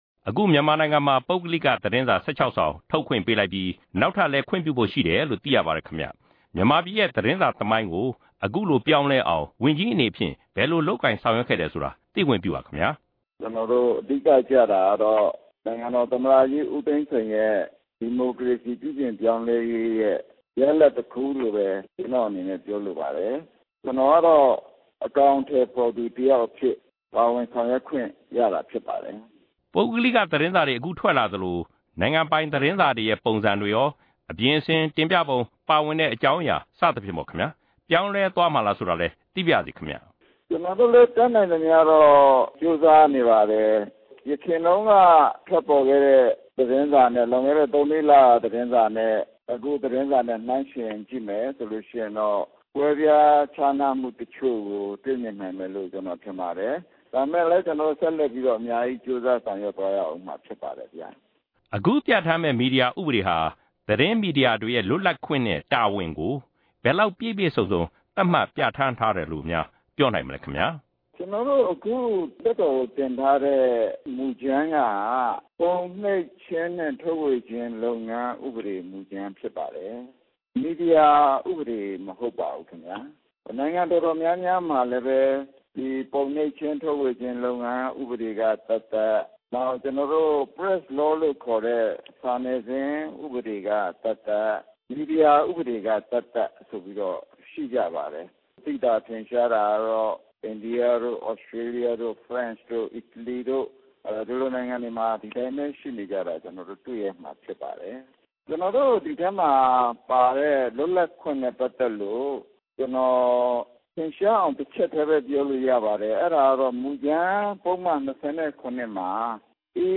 လက်ရှိသတင်းမီဒီယာလောက ပြောင်းလဲမှုတွေနဲ့ ပတ်သက်ပြီး RFA က ဆက်သွယ်မေးမြန်းခဲ့ရာမှာ ဝန်ကြီး ဦးအောင်ကြည်က ခုလိုပြောဆိုလိုက်တာ ဖြစ်ပါတယ်။
RFA နဲ့ ပြန်ကြားရေးဝန်ကြီး ဦးအောင်ကြည် ဆက်သွယ်မေးမြန်းထားတာကို နားဆင်နိုင်ပါတယ်။